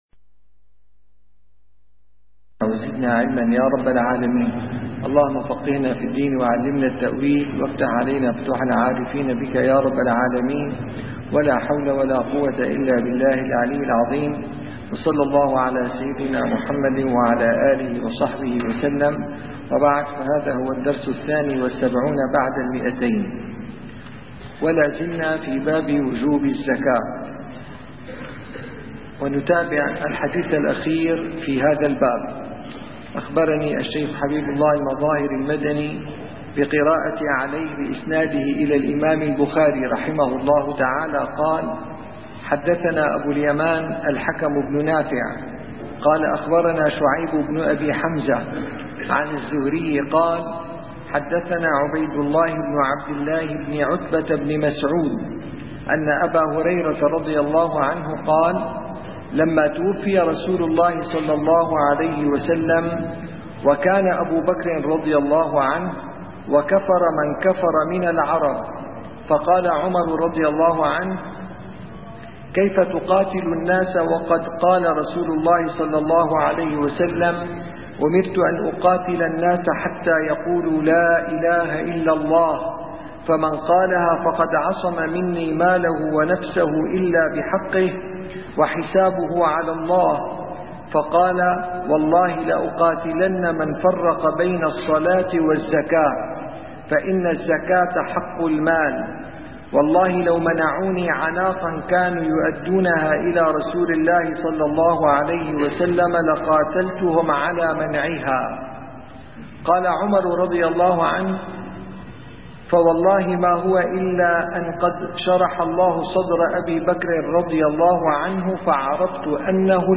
- الدروس العلمية - شرح صحيح البخاري - كتاب الزكاة الحديث 1399 - 1400